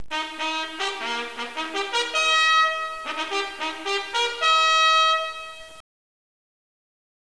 � � Regimental call � � � Men of Harlech � � � � � � � � � � � � �
call.wav